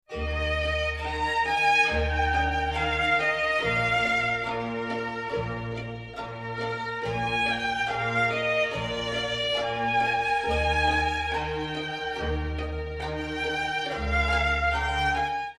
Largo